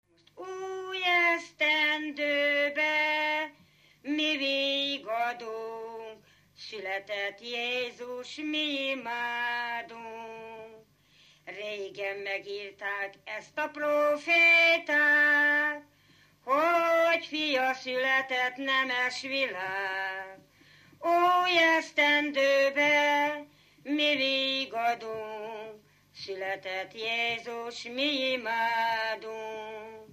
Alföld - Arad vm. - Vadász
ének
Stílus: 7. Régies kisambitusú dallamok
Szótagszám: 10.10.9.9
Kadencia: X (X) X 1